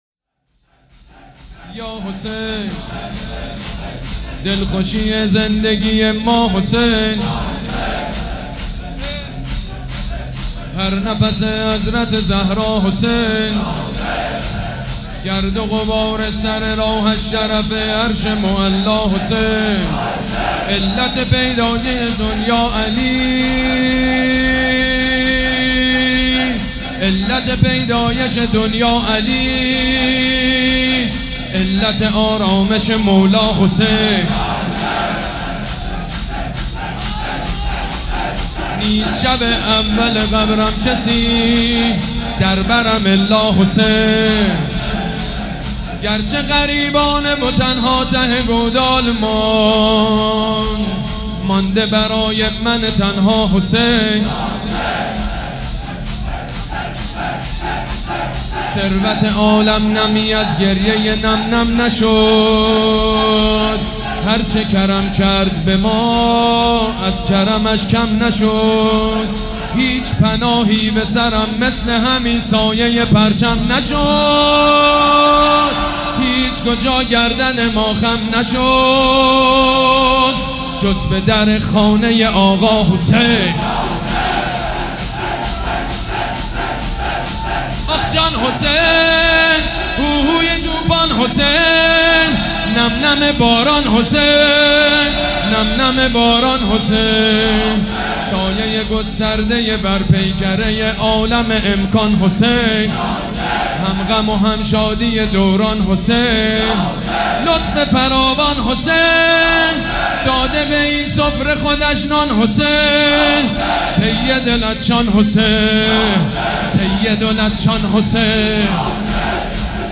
دومین شب از مـــراســم عـــزاداری دهــه اول مـــحــرم الـحــرام در هیئت ریحانه الحسین
برچسب ها: سینه زنی ، شب د ، بنی فاطمه ، امام حسین ، هیئت